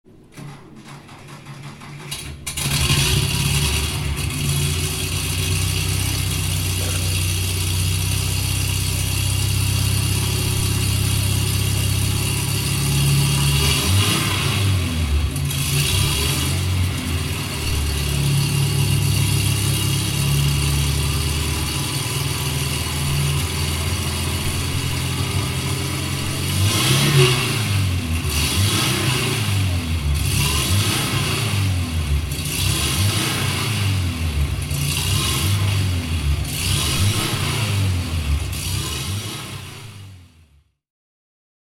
Porsche 356 Nummer 1 (1948) - Laufgeräusch